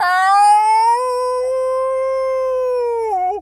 pgs/Assets/Audio/Animal_Impersonations/wolf_howl_06.wav at master
wolf_howl_06.wav